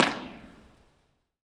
Concrete, sand.
Download this impulse response (right click and “save as”)